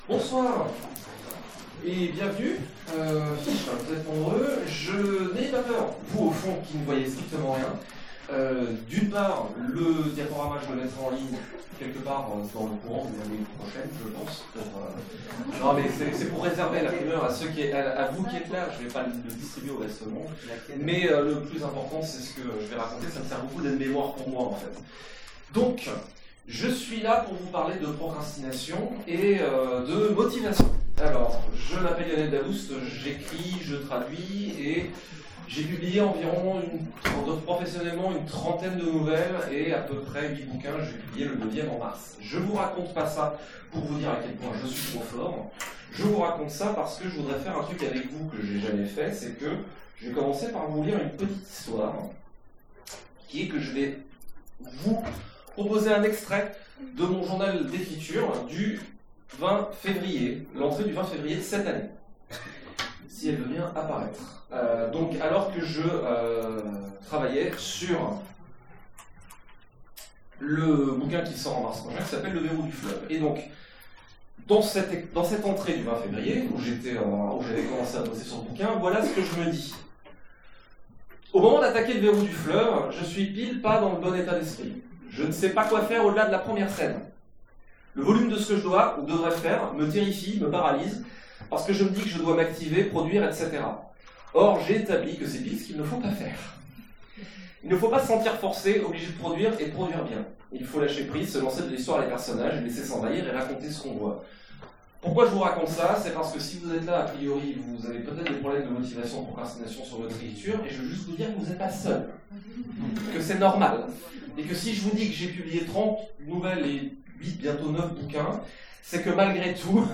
Utopiales 2017 : Cours du soir Procrastination de l’écrivain
Mots-clés Ecriture Conférence Partager cet article